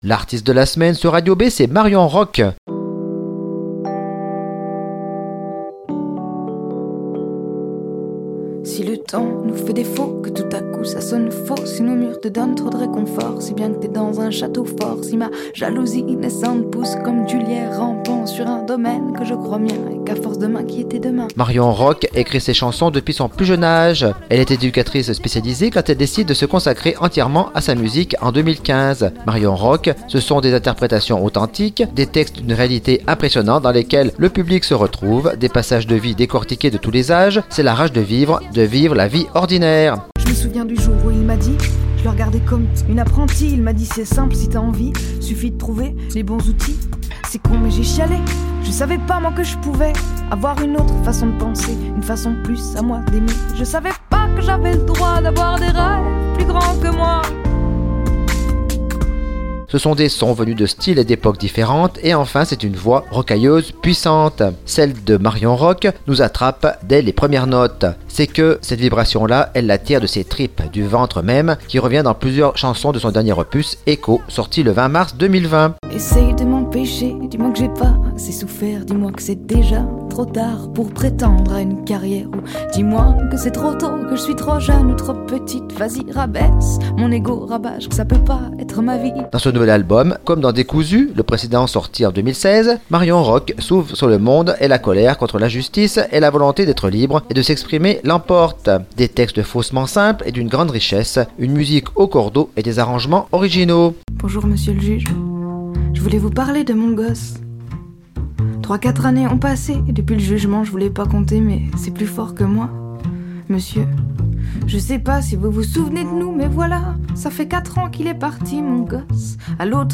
percussions et beat-box
contrebasse